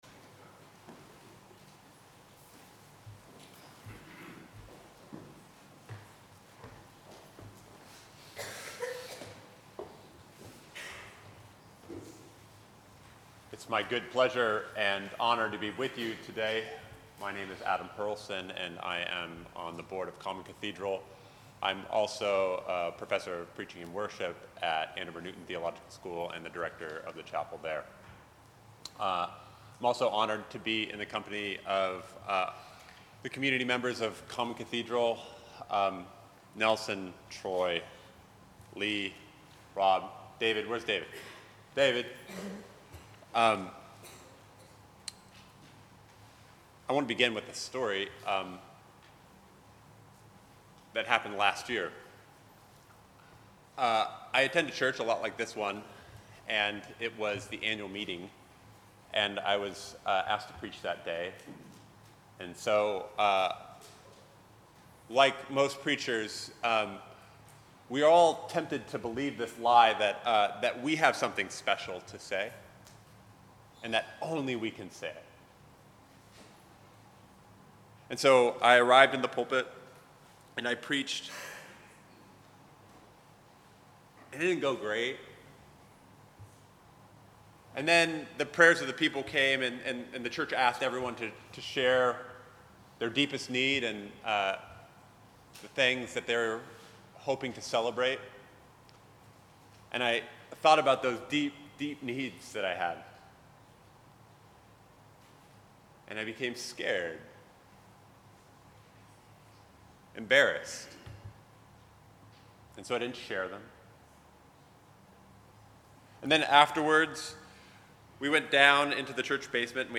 Common Cathedral preaching after the Harvest Fair (October 1)